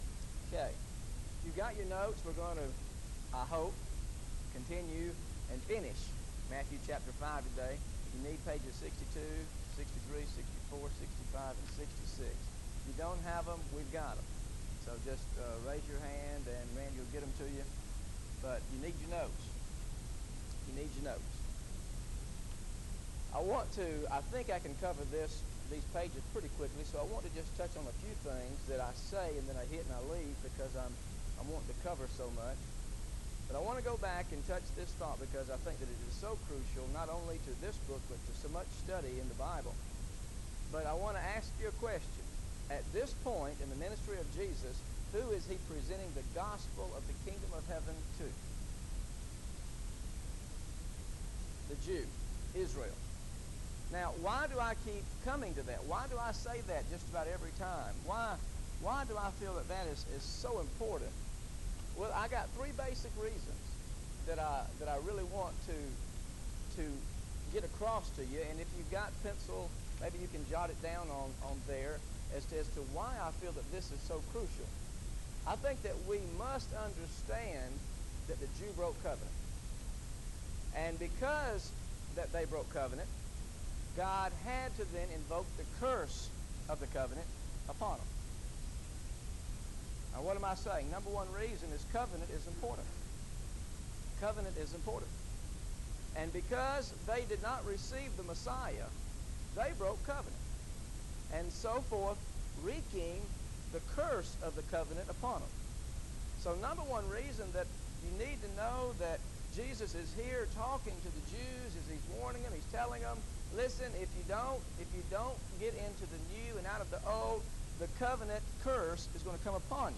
Listen to the Teaching on Matthew 5 Part 4 Audio